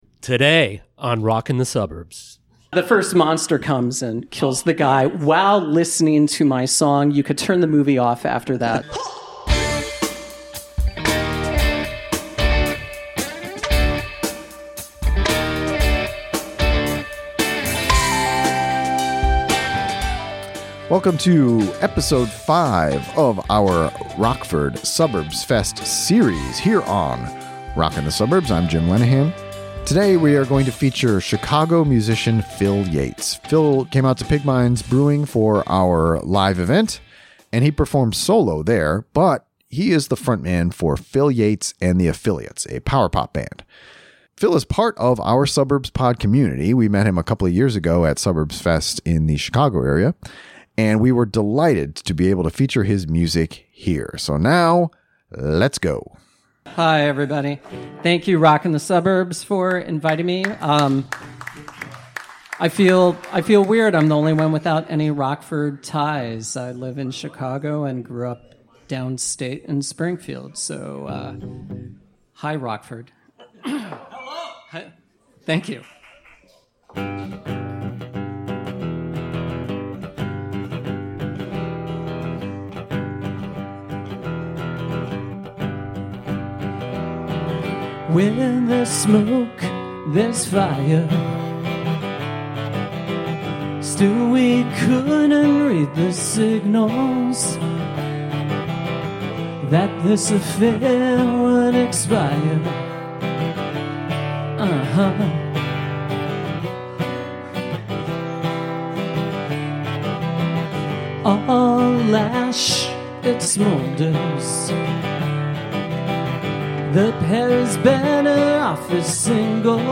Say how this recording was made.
Episode 5 from our Live from Suburbs Fest series.